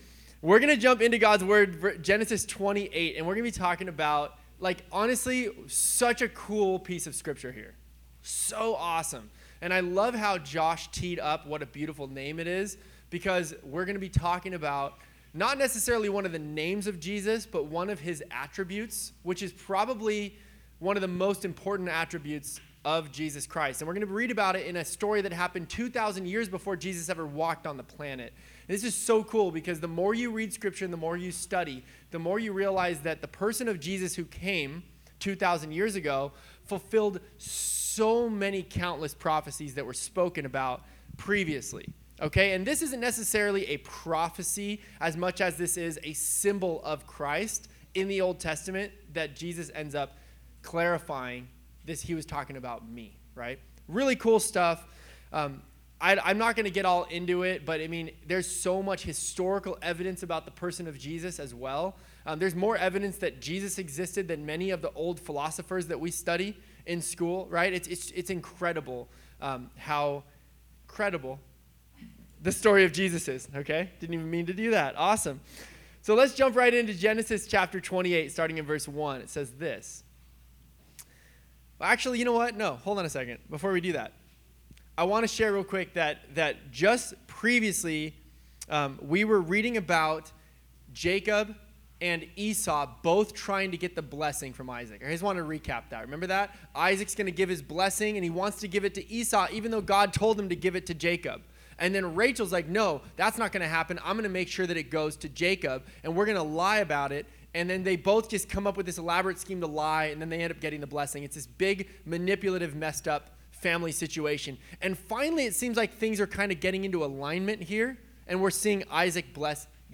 Sermons | Revive Church